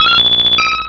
Cri de Persian dans Pokémon Rubis et Saphir.